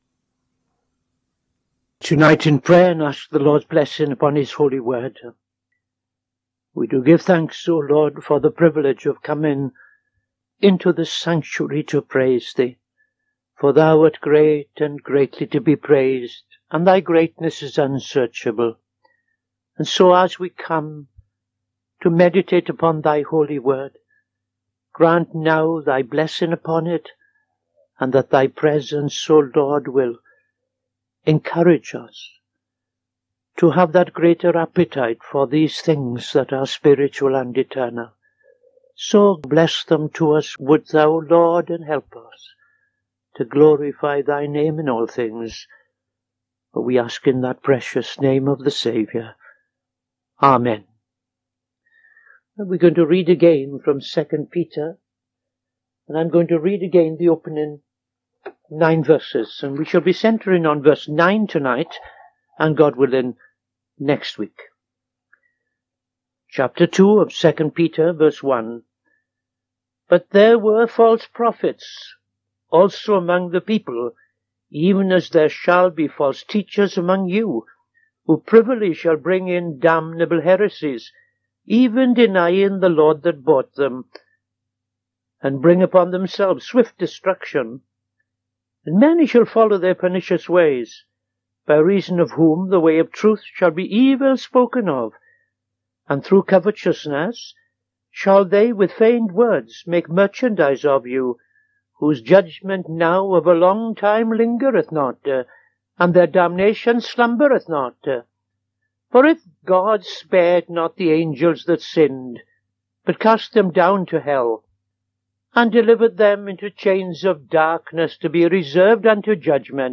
Opening Prayer and Reading II Peter 2:1-9